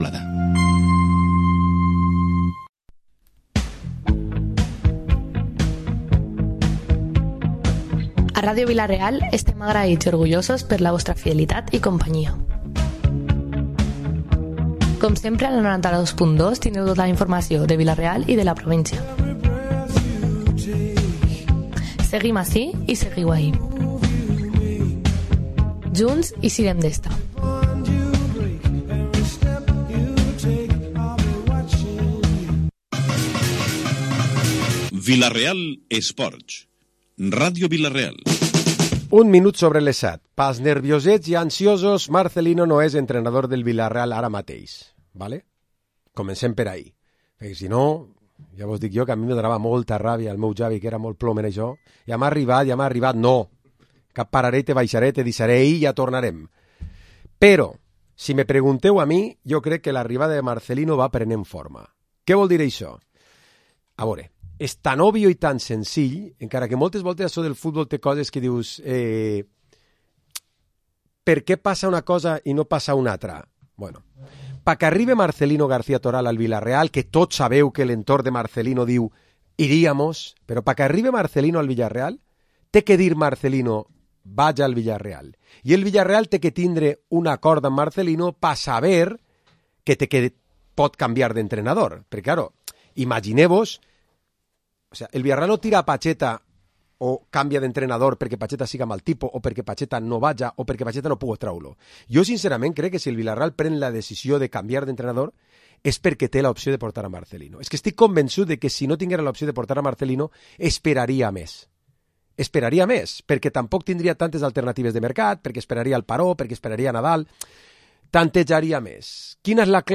Programa esports tertúlia dilluns 6 de Novembre